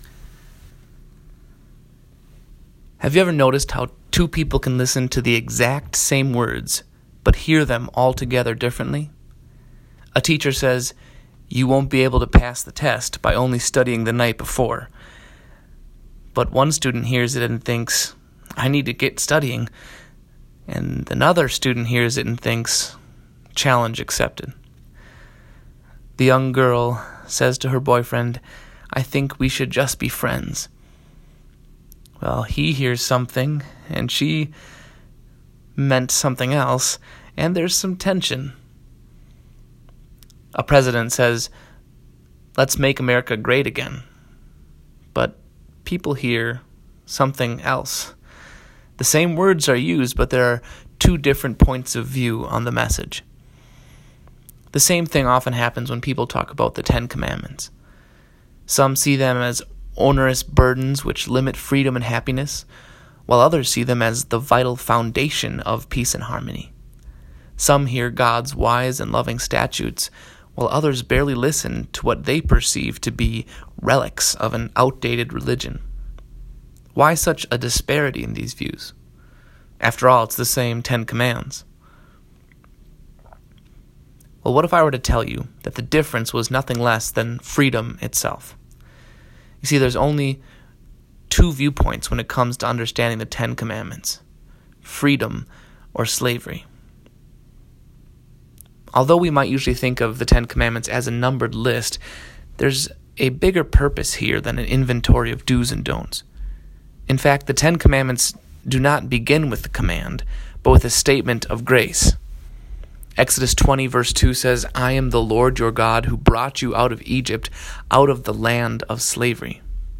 Exodus-20_1-17-Sermon.m4a